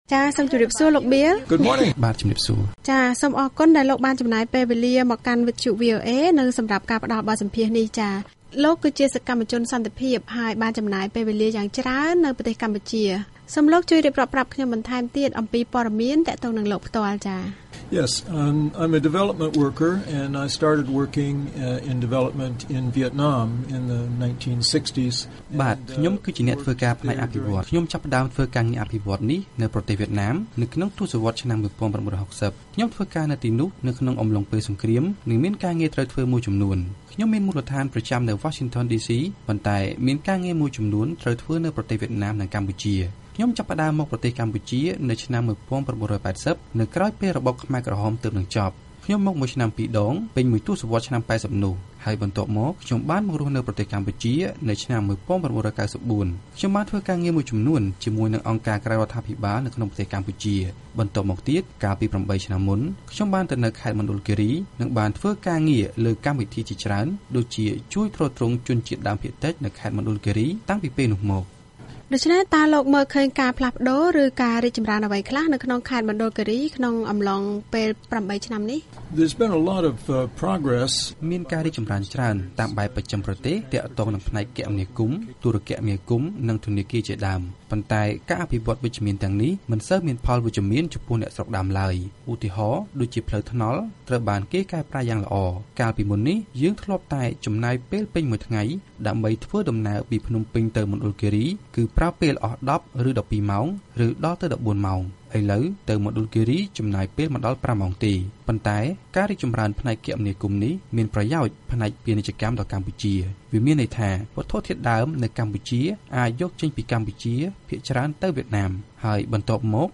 បទសម្ភាសន៍ VOA៖ ការព្យាយាមអភិរក្សវប្បធម៌របស់ជនជាតិដើមភាគតិចនៅខេត្តមណ្ឌលគីរី (ភាគ១)